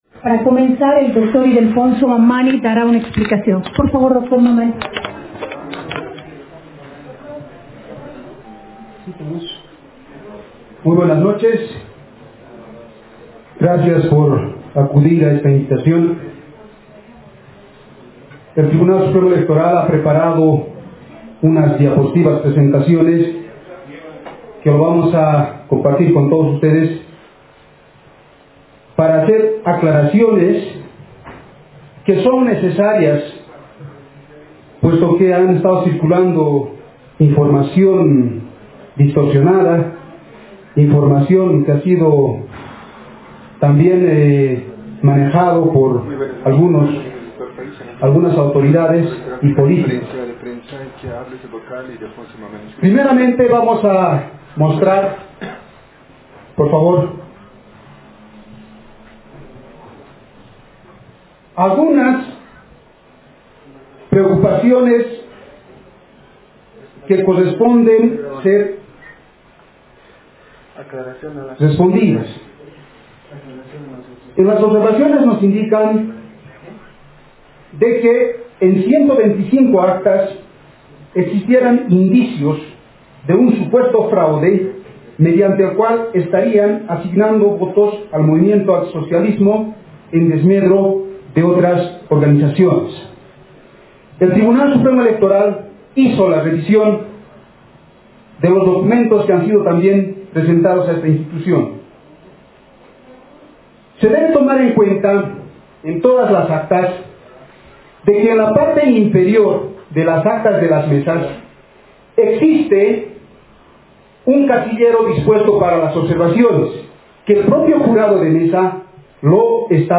Descargue la primera parte de la conferencia